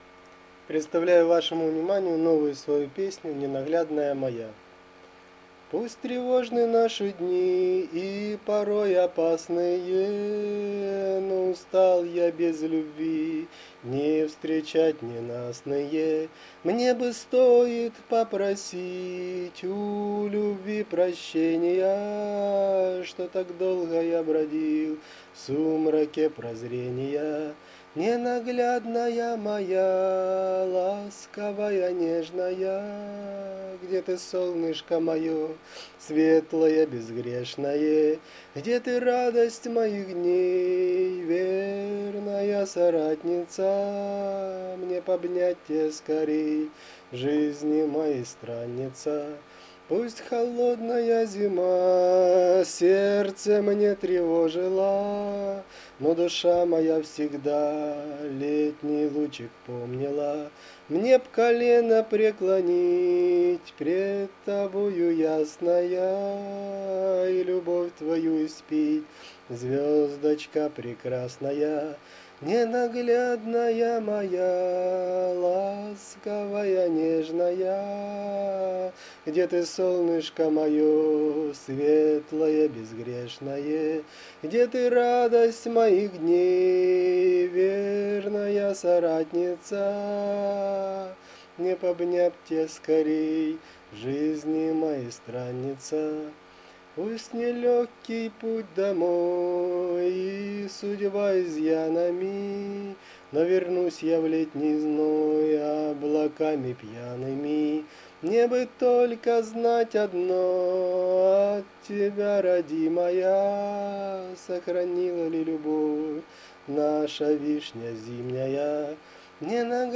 Рубрика: Поезія, Авторська пісня
Нежно, чувственно, с легкой печалью tender 16 give_rose